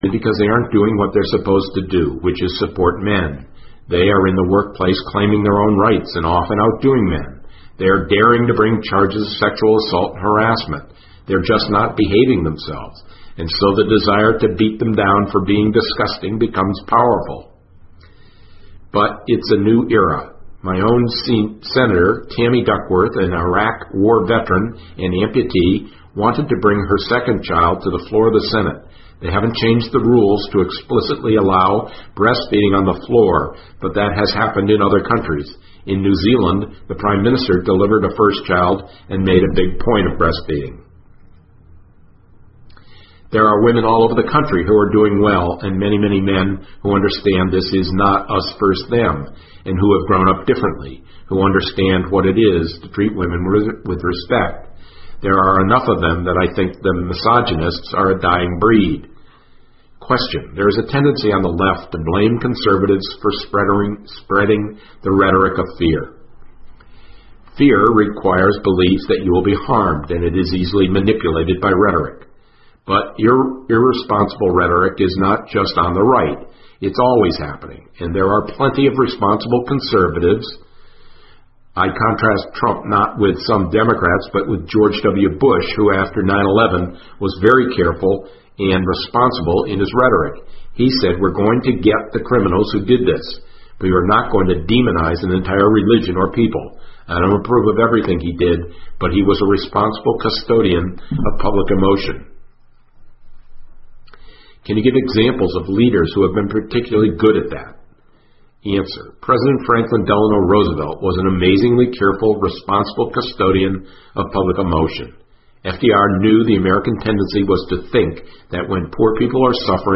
新闻周刊:娜斯鲍姆专访:特朗普的恐惧厌恶策略可以被击败(3) 听力文件下载—在线英语听力室